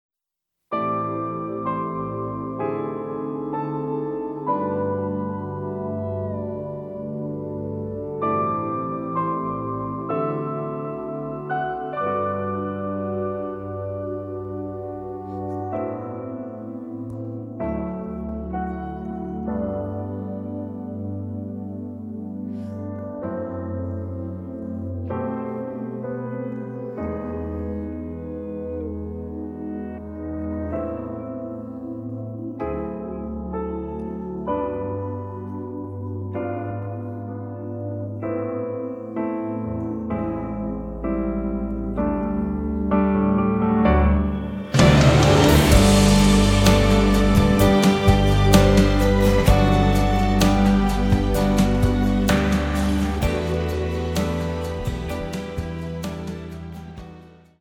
음정 원키 3:51
장르 가요 구분 Voice Cut